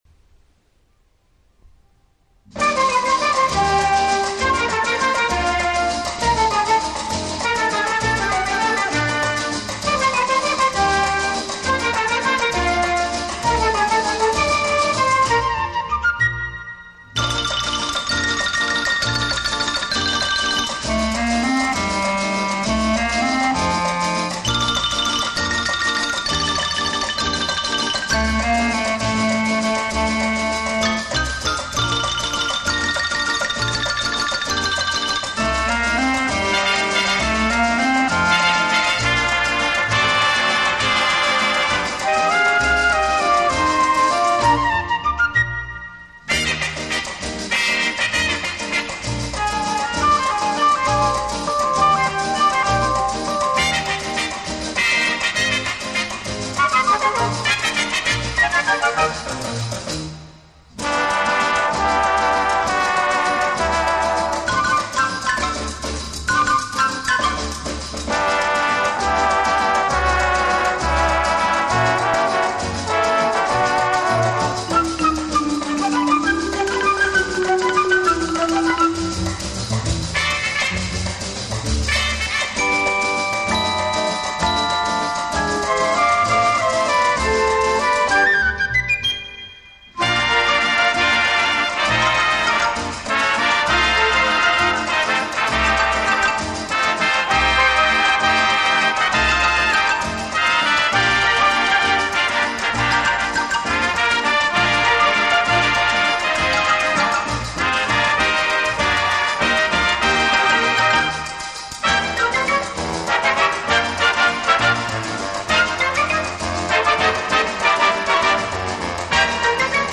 斯把拉丁的浪漫、神秘的情结改良的几乎丧失，只留下奔放和热烈，感觉很吵。